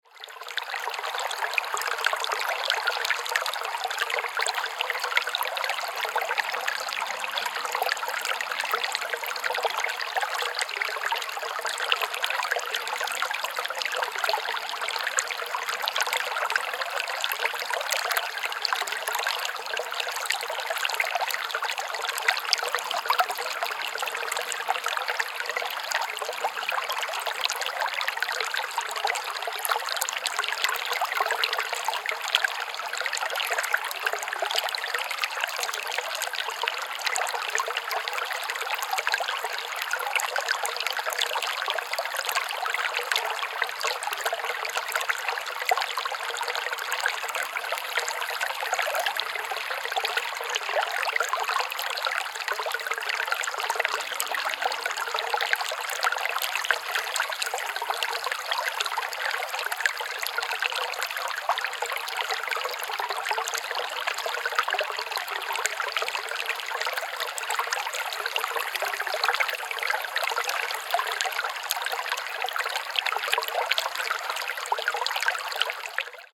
Forest Stream Babbling Sound Effect
Description: Forest stream babbling sound effect. Enjoy the gentle ASMR of a forest stream babbling, delivering relaxing nature ambience perfect for meditation, sleep, focus, or calming background audio. Water sounds.
Forest-stream-babbling-sound-effect.mp3